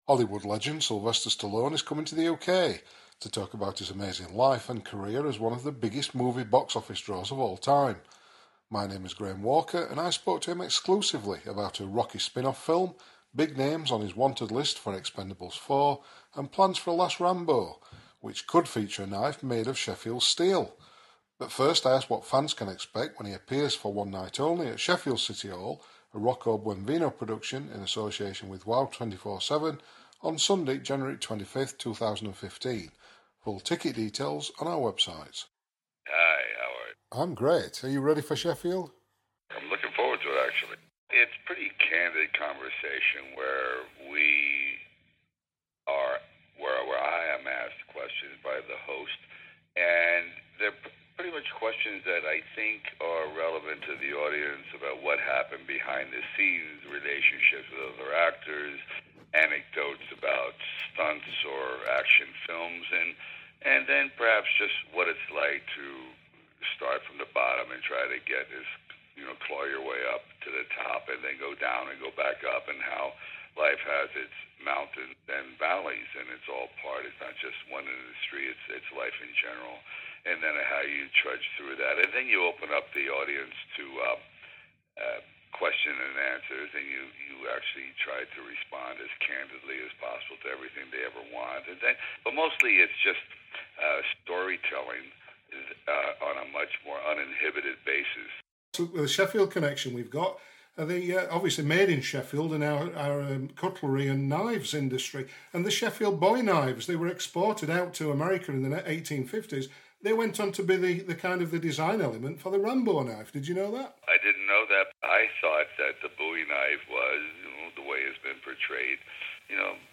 interview audio, Sylvester Stallone indique qu'Arnold Schwarzenegger pourrait persuader Clint Eastwood de rejoindre les Expendables... et qu'il aimerait avoir Jack Nicholson en "méchant".